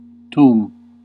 Ääntäminen
Synonyymit deinde Ääntäminen Classical: IPA: /tum/ Haettu sana löytyi näillä lähdekielillä: latina Käännös Ääninäyte Adverbit 1. then UK US 2. thereupon Esimerkit Tum Cornelia in horto sedet.